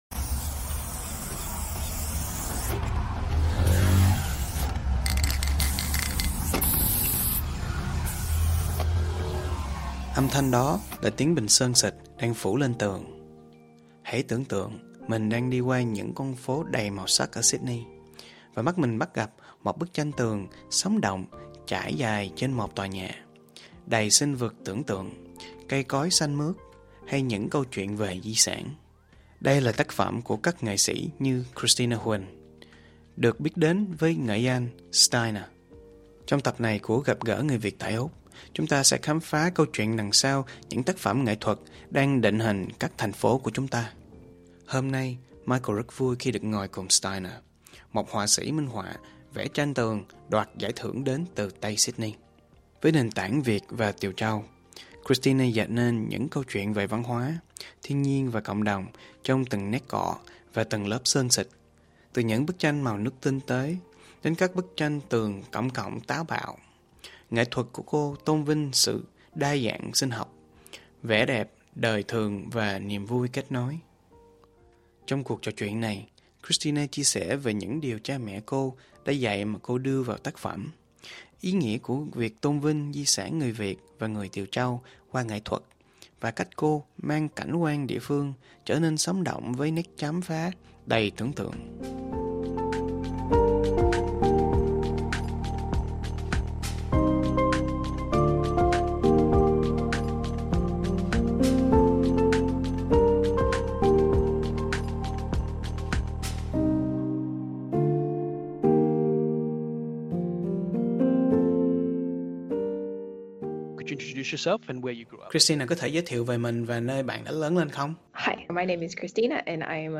Gặp gỡ người Việt ở Úc